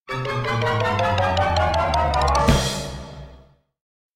comedy_music_falling_down_stairs